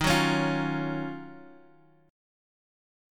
Ebm9 Chord
Listen to Ebm9 strummed